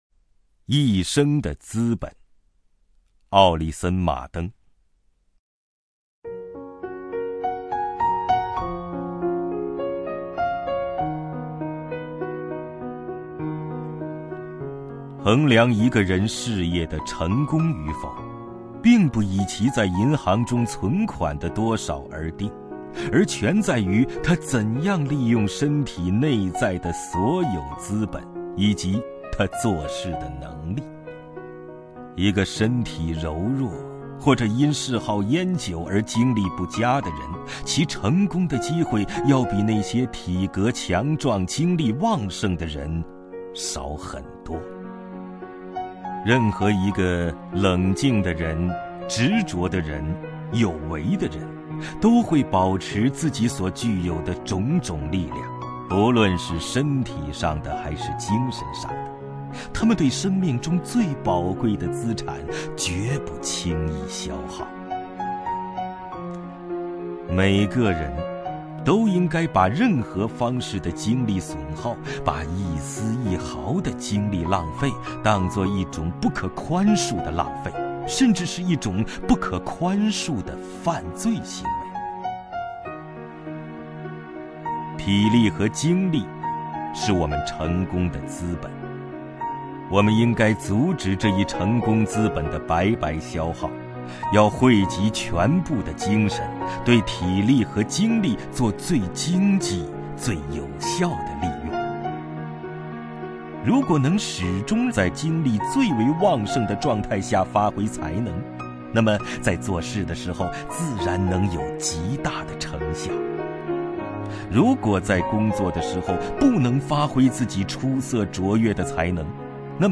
首页 视听 名家朗诵欣赏 王凯
王凯朗诵：《一生的资本》(（美）奥里森·马登)
YiShengDeZiBen_OrisonMarden(WangKai).mp3